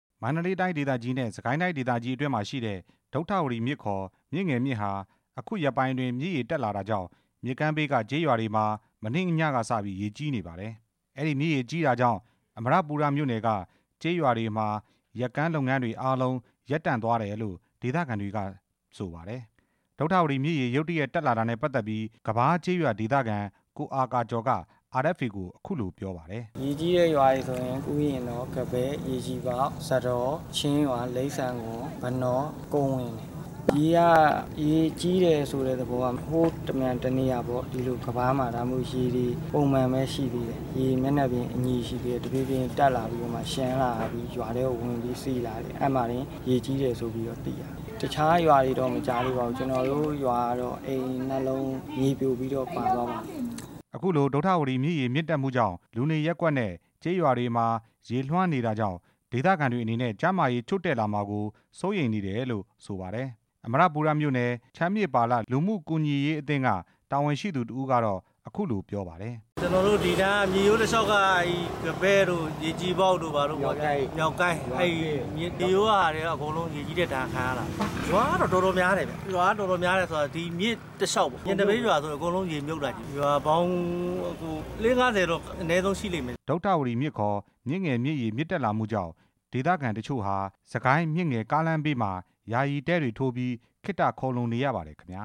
ရေကြီးမှုအကြောင်း တင်ပြချက်